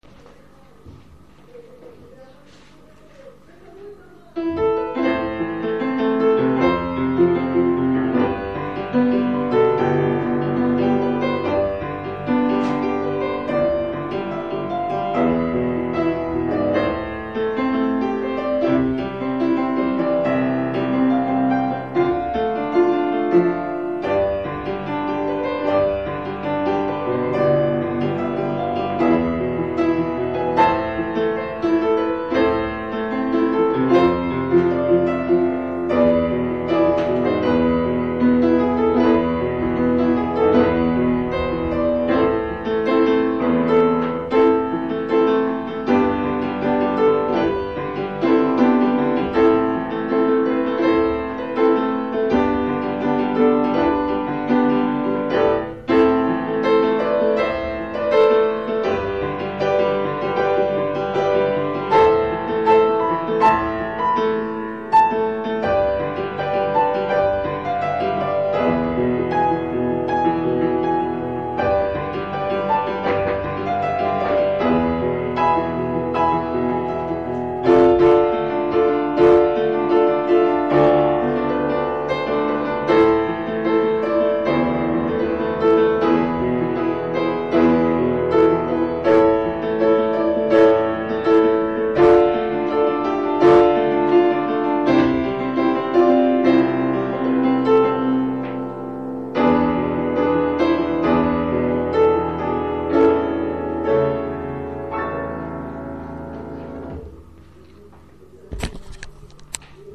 שווה לראות איך ניתן למקם את המיקרופון כך שישמיע צליל איכותי יותר מהטונים הנמוכים יותר.
מנגינה מרגשת ויפה!